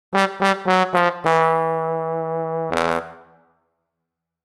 Loser Trumpet Sound Effect Free Download
Loser Trumpet